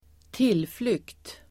Uttal: [²t'il:flyk:t]
tillflykt.mp3